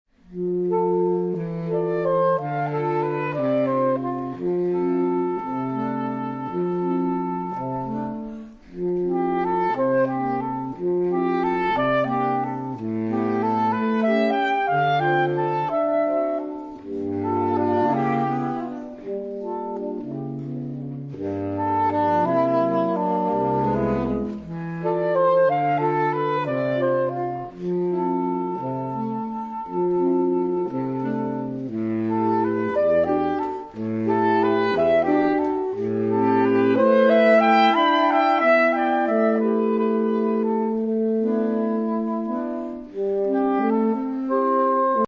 Obsazení: 4 Saxophone (SATBar)